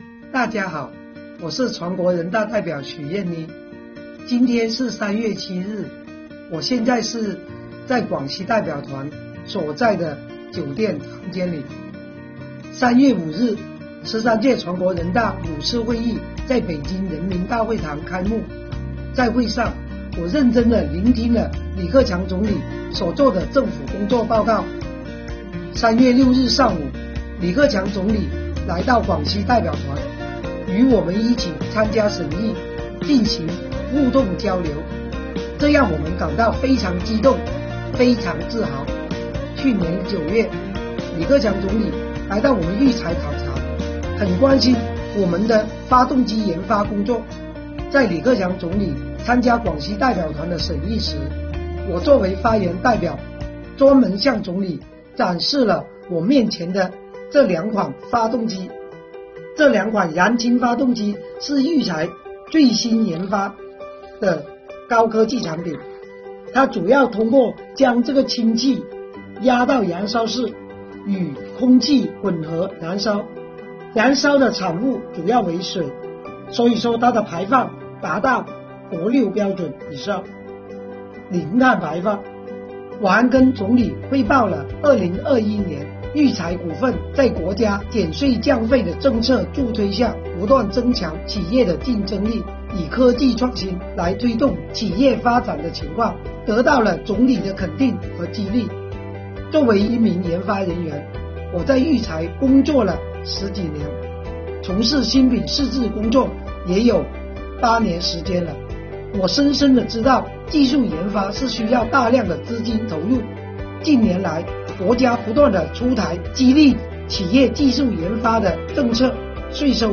李克强总理参加广西代表团审议
来自玉柴集团的全国人大代表许燕妮围绕增强制造业企业竞争力等内容进行了发言。